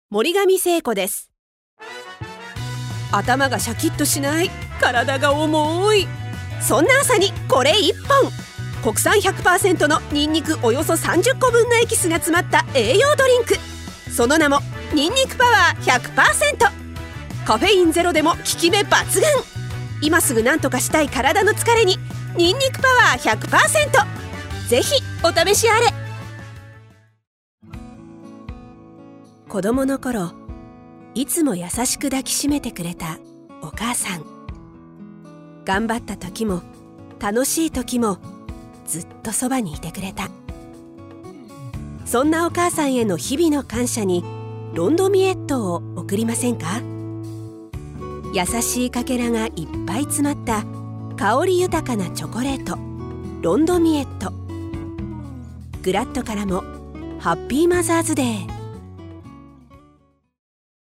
ボイスサンプル
• 穏やかでまろやかな声
• 音域：高～中音
• 声の特徴：穏やか、明るい、説得力
• CM